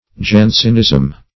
jansenism - definition of jansenism - synonyms, pronunciation, spelling from Free Dictionary
Jansenism \Jan"sen*ism\, n. [F. Jans['e]nisme.] (Eccl. Hist.)